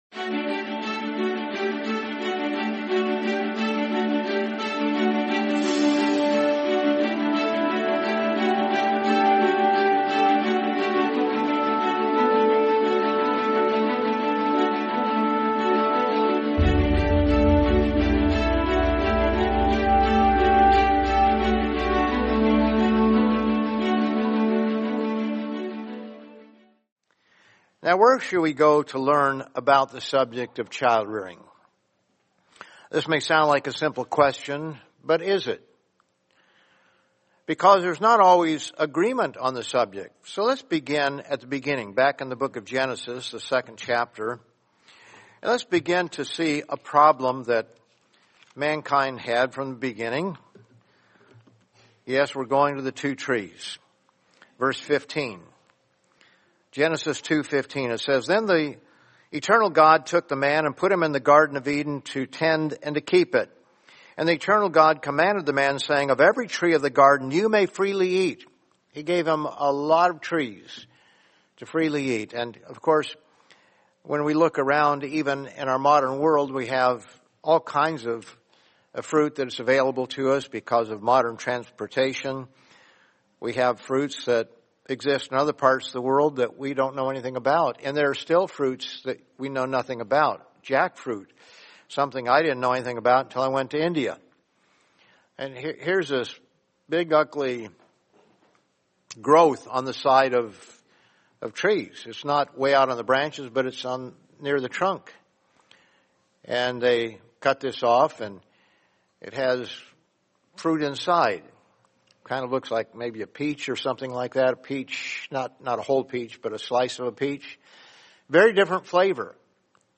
Welcome to the Living Church of God’s audio sermon podcast feed where you will find sermons on topics including Prophecy, Christian Living, Bible Teachings, current National and World News, and trends.